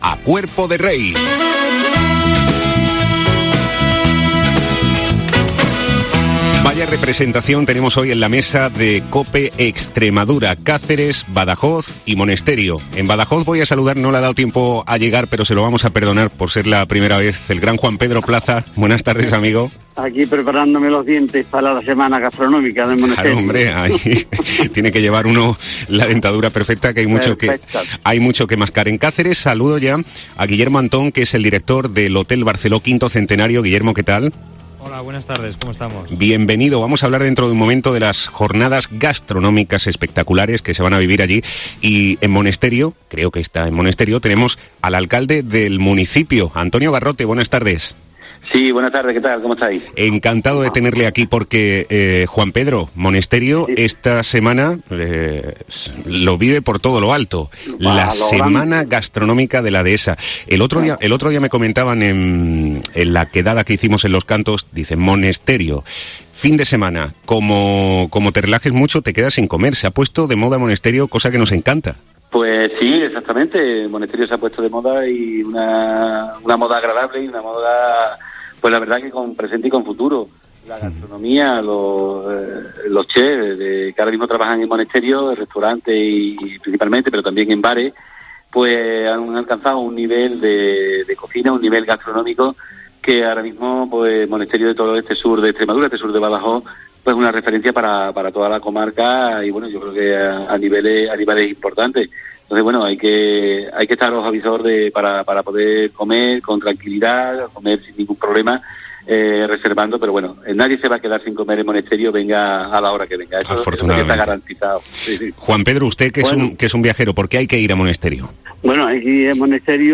El alcalde de la localidad, Antonio Garrote, ha pasado por 'A Cuerpo de Rey'